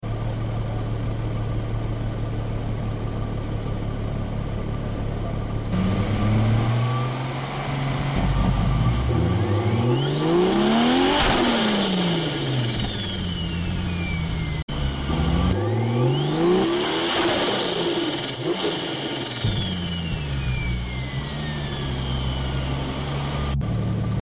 Some spooley sound asmr